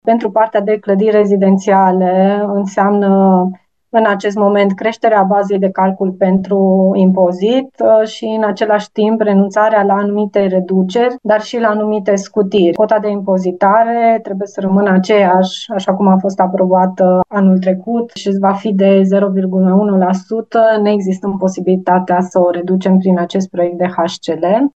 Impozitul pentru clădirile nerezidențiale va scădea ușor, iar reducerile acordate până acum în funcție de vechimea imobilului vor fi eliminate, spune viceprimarul Paula Romocean.